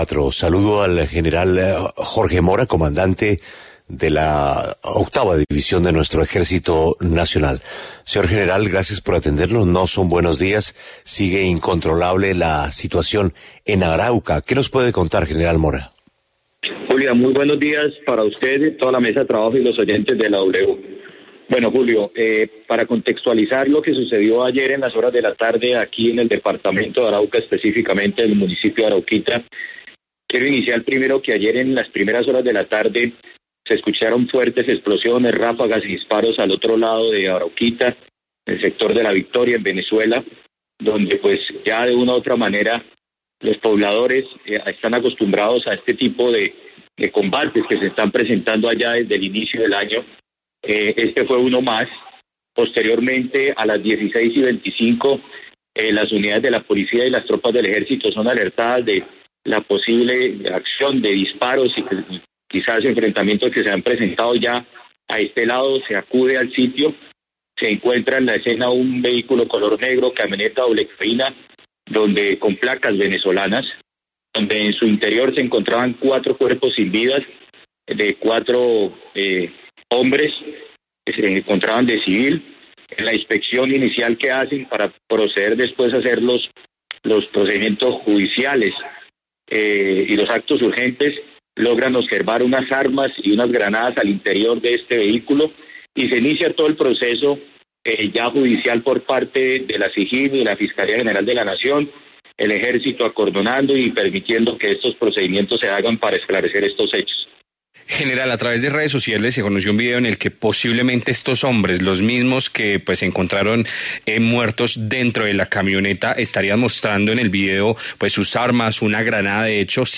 En diálogo con La W, el general Jorge Eduardo Mora, comandante de la Octava División del Ejército, se refirió a las circunstancias en las que murieron cuatro personas en inmediaciones del municipio de Arauquita.